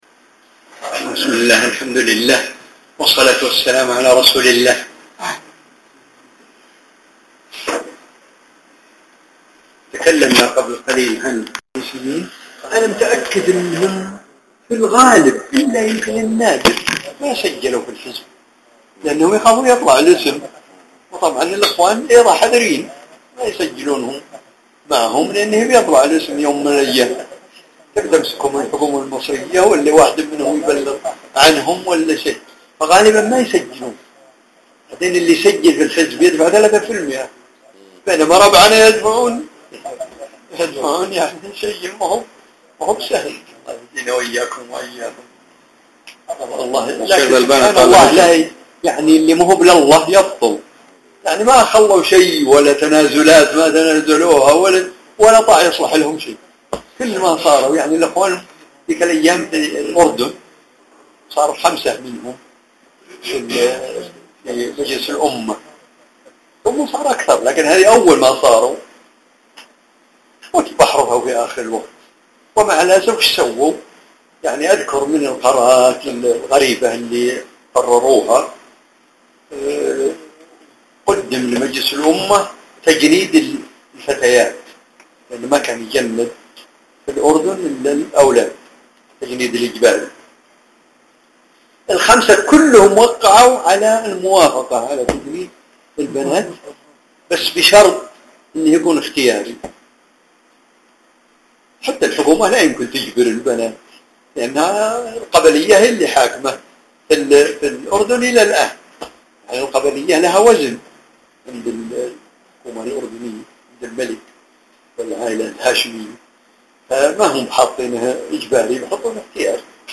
لقاء في ديوان